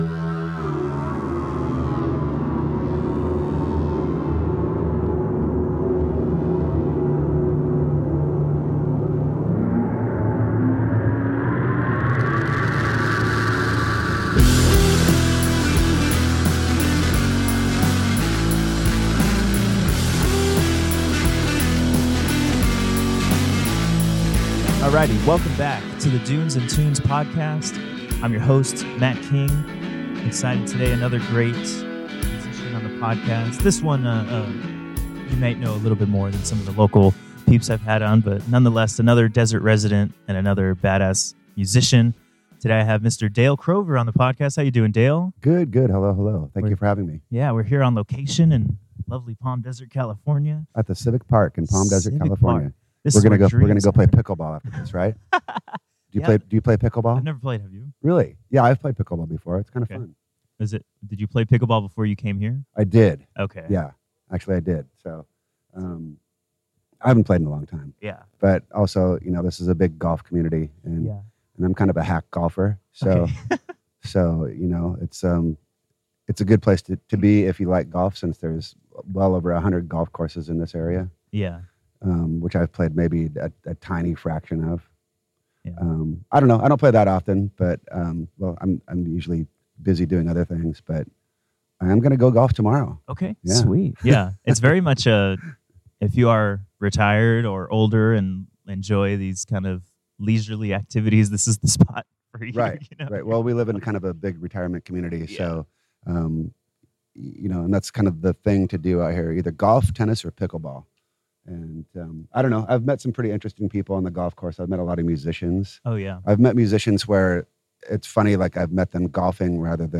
During this episode of Dunes & Tunes, I chatted with Dale Crover, the iconic drummer behind stoner sludge legends Melvins, glam punk outfit Redd Kross, his solo project Dale Crover Band, and he was even a member of Nirvana for a short time. On this podcast, we talked about Dale’s time in the desert, golfing, pickleball, touring with Melvins, staying busy, and so much more.